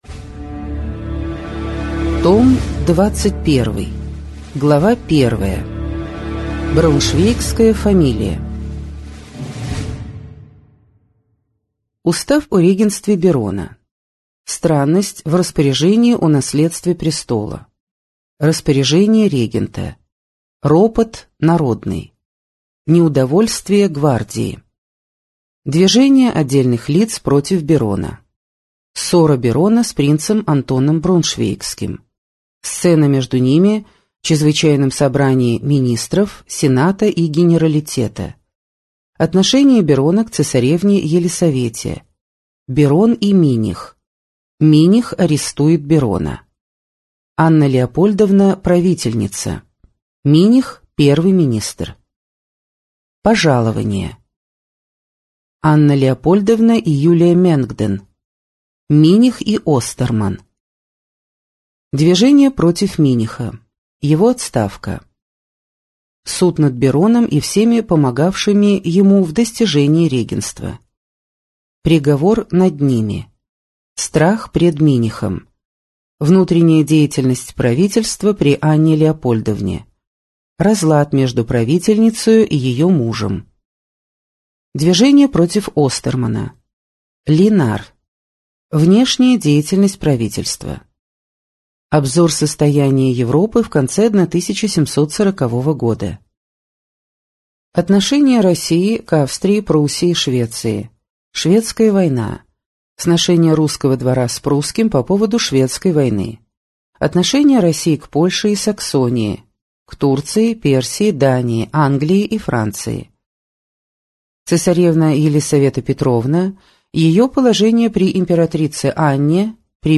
Аудиокнига История России с древнейших времен. Том 21. Царствование императрицы Елисаветы Петровны. 1740–1744 гг.